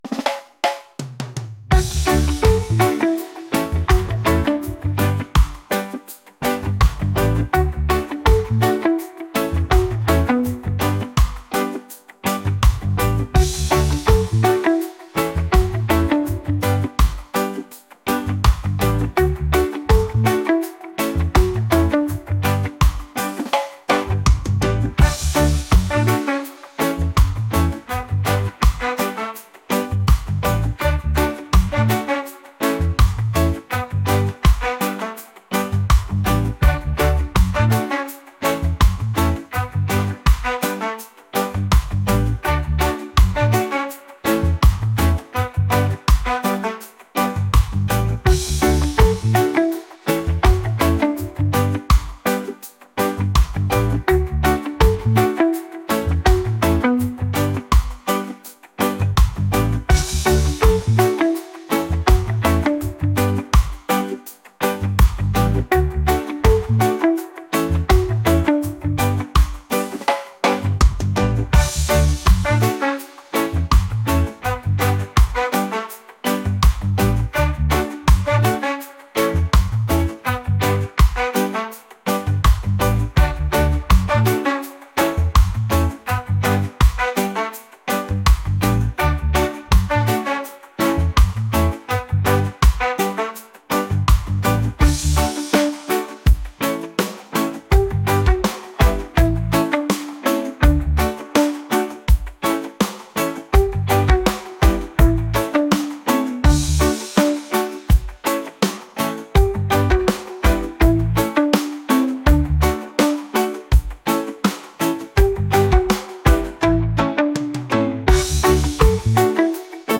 reggae | pop | ska